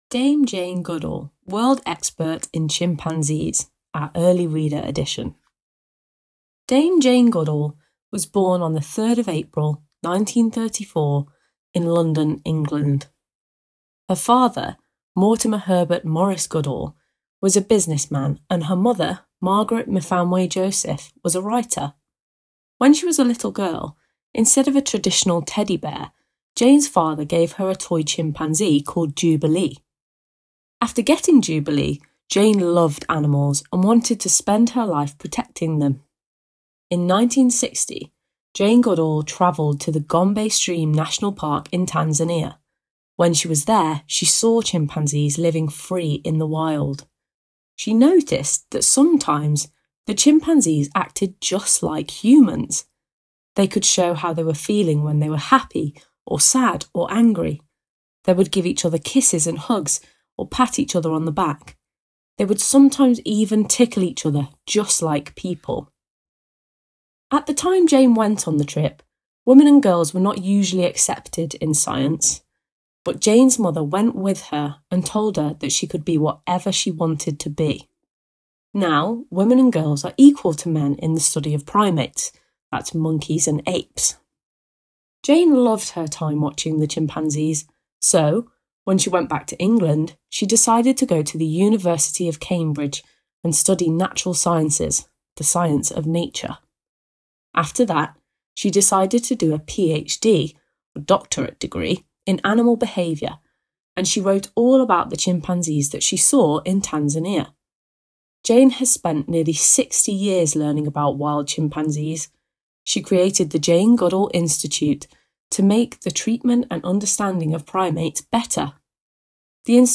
Share Description Listen along to this early reader story about Dame Jane Goodall, a world expert in chimpanzees.